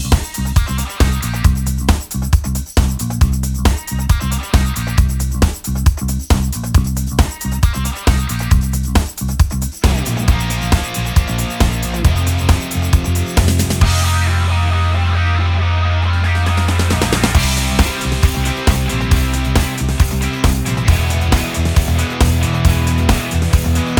Minus Main Guitar Pop (1990s) 3:36 Buy £1.50